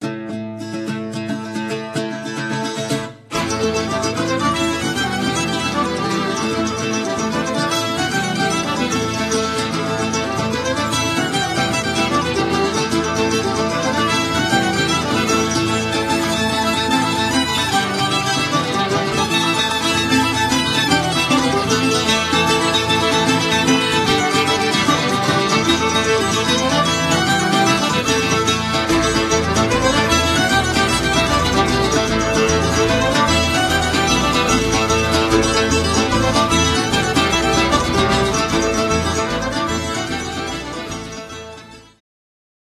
jigs